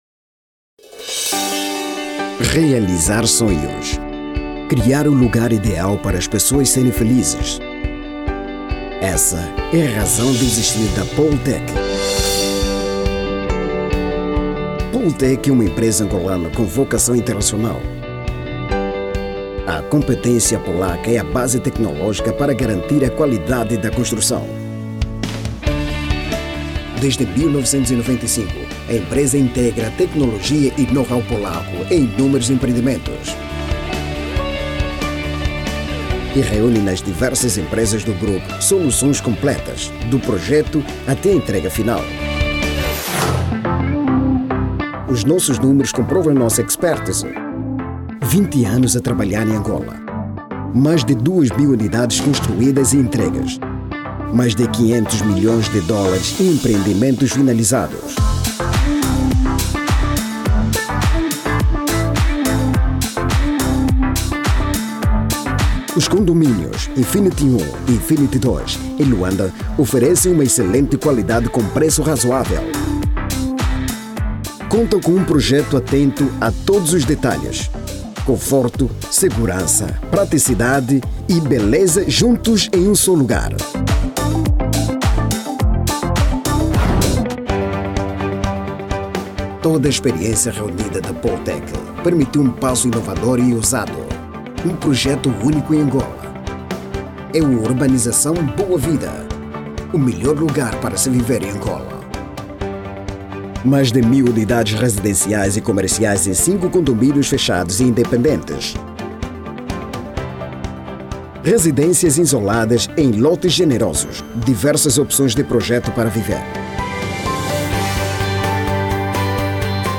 Masculino
Voz Padrão - Grave 02:55